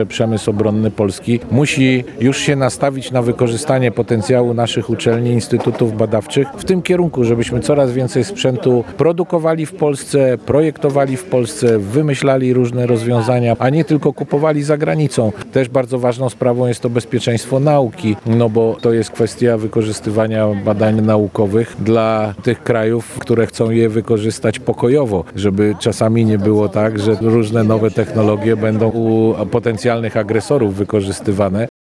Przebywający dziś (22.05) w Chełmie szef resortu nauki minister Dariusz Wieczorek podkreślił, że duże znaczenie dla utrzymania bezpieczeństwa ma współpraca przemysłu obronnego z ośrodkami naukowo-badawczymi.